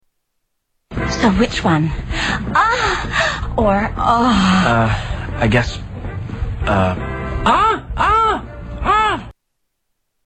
Moans
Category: Television   Right: Personal